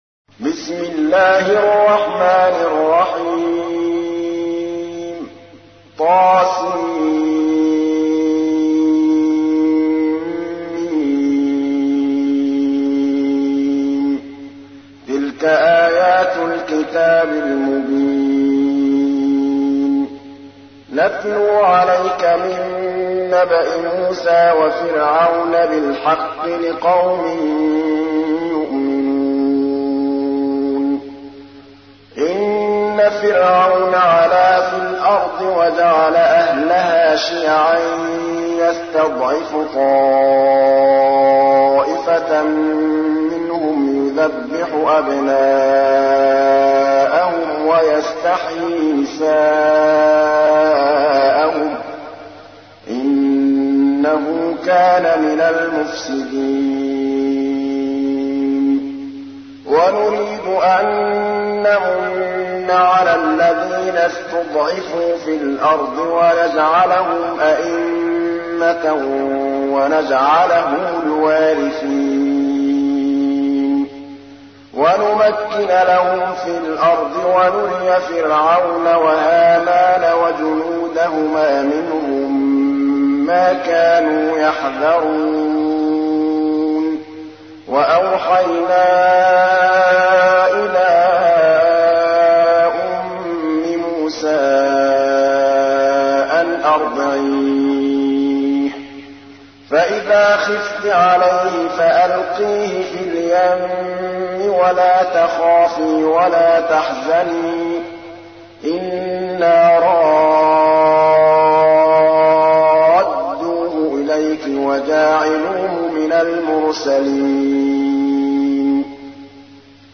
تحميل : 28. سورة القصص / القارئ محمود الطبلاوي / القرآن الكريم / موقع يا حسين